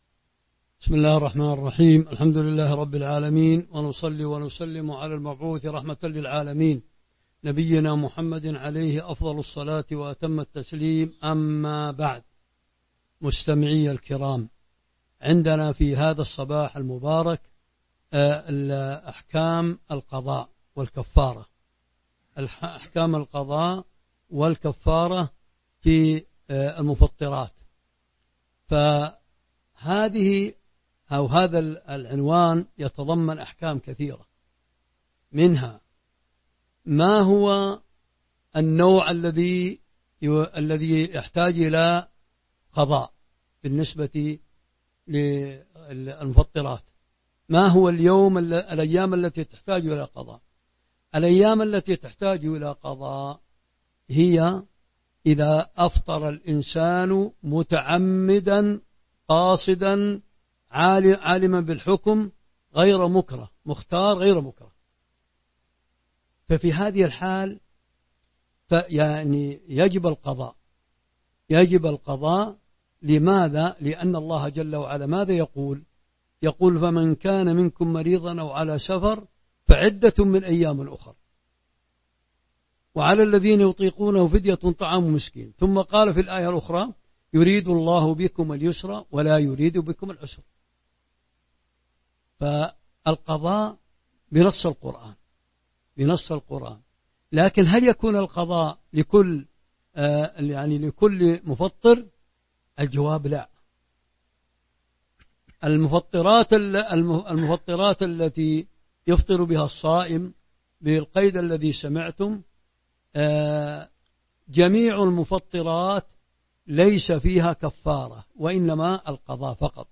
تاريخ النشر ٨ رمضان ١٤٤٢ هـ المكان: المسجد النبوي الشيخ